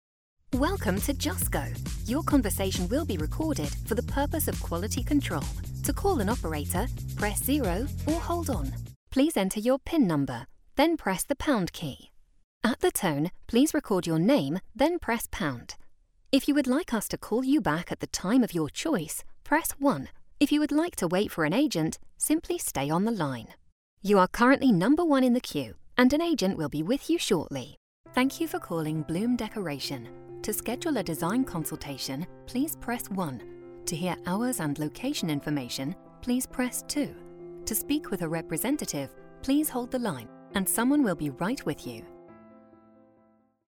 Engels (Brits)
Natuurlijk, Volwassen, Warm, Zacht, Zakelijk
Telefonie